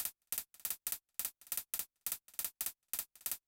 ROLLING HH-R.wav